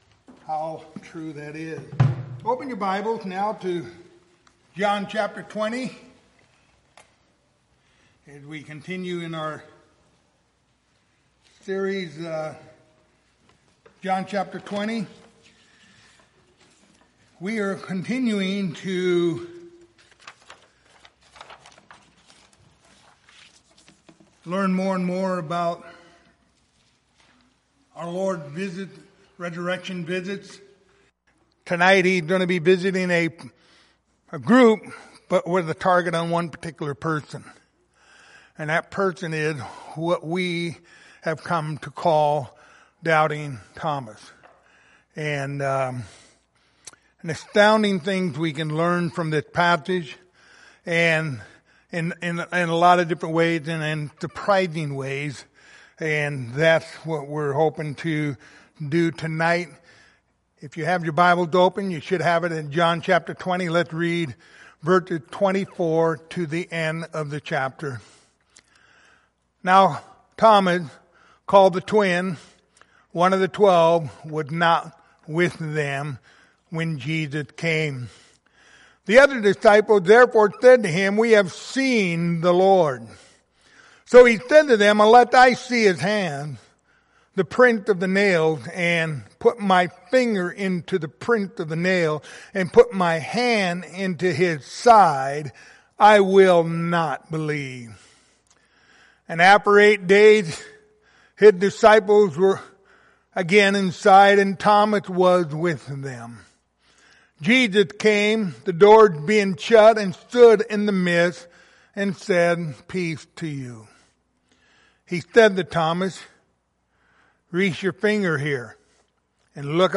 Passage: John 20:24-29 Service Type: Wednesday Evening